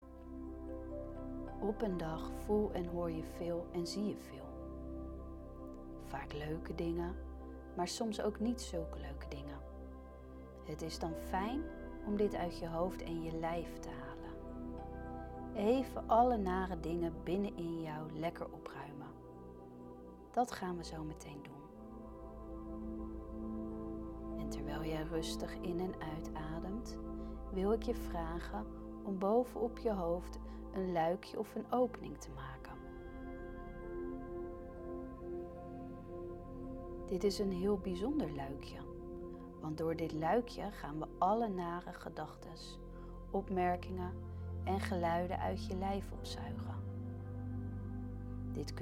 Meditatie-hoofd-leegmaken-preview.mp3